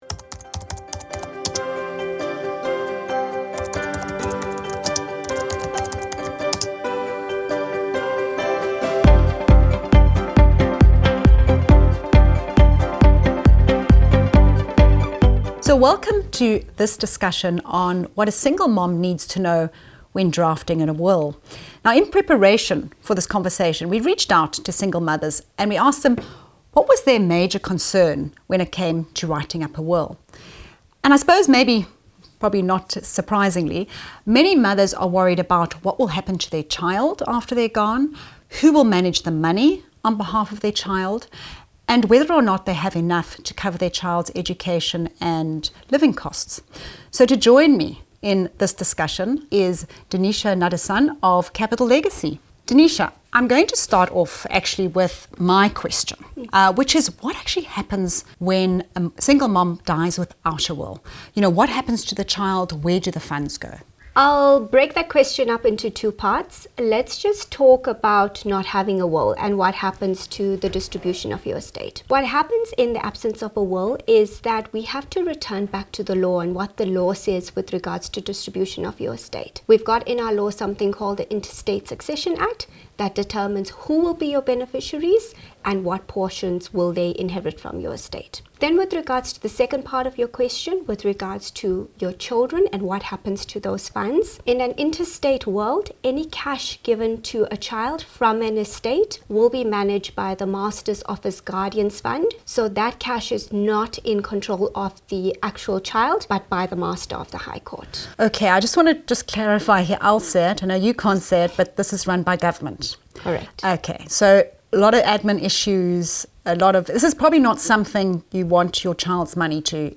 · How much does it cost to draw up a will · Who should be the executor While this was a discussion about single moms, it is relevant to any parent.